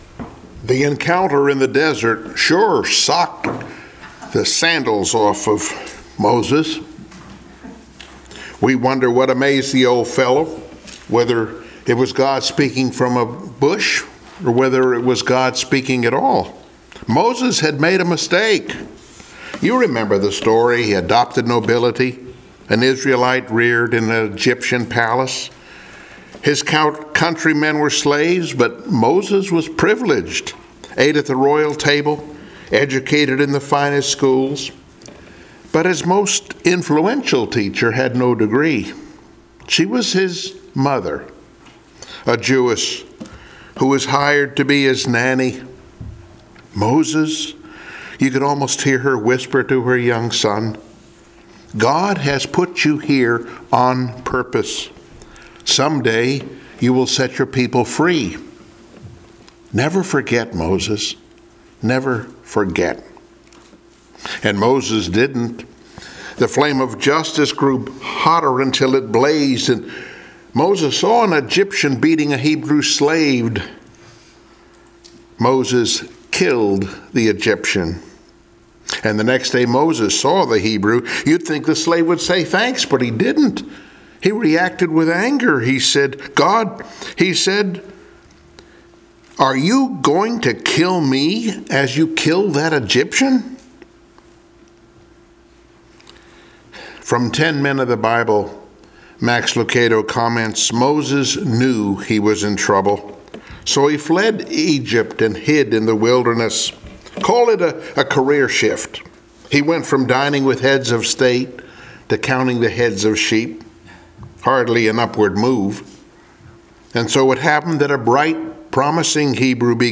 Cast of Characters Passage: Exodus3:1-6,9-10 Service Type: Sunday Morning Worship « “Then Noah Knew…”